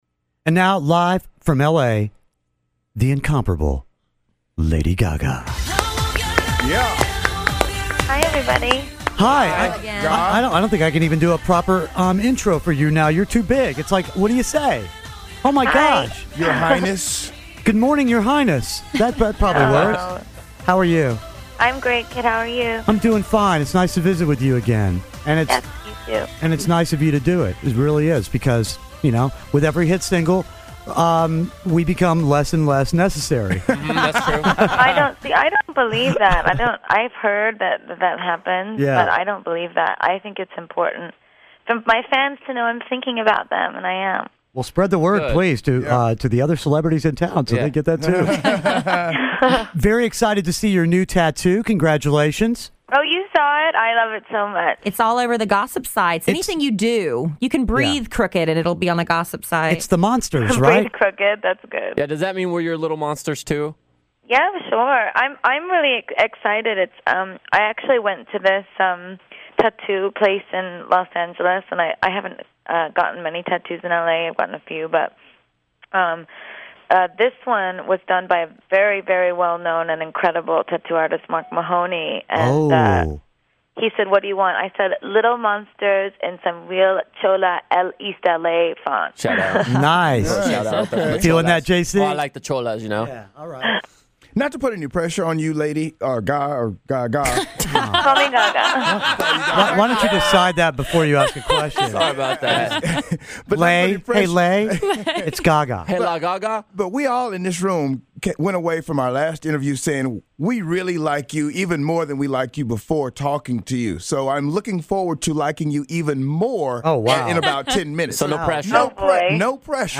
Lady Gaga Interview
Kidd Kraddick in the Morning interviews Lady Gaga!